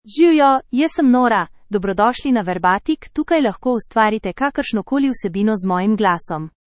Nora — Female Slovenian AI voice
Nora is a female AI voice for Slovenian (Slovenia).
Voice sample
Listen to Nora's female Slovenian voice.
Female
Nora delivers clear pronunciation with authentic Slovenia Slovenian intonation, making your content sound professionally produced.